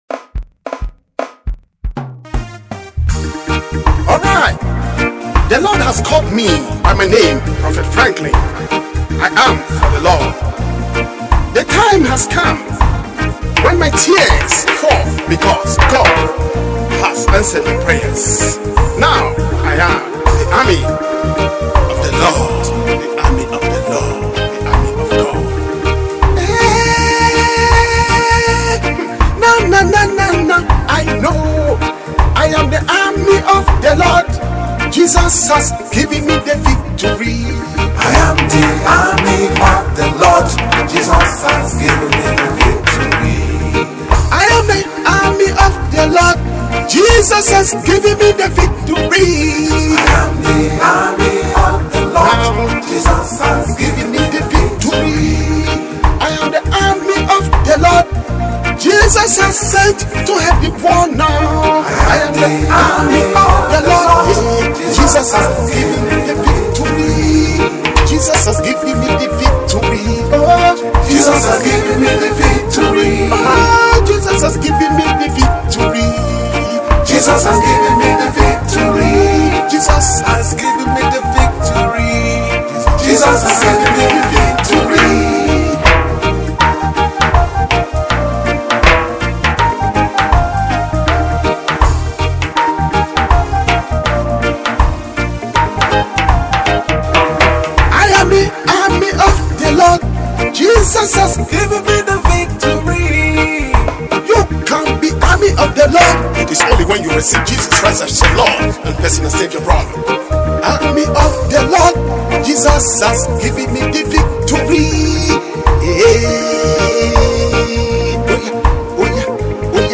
spiritual / gospel song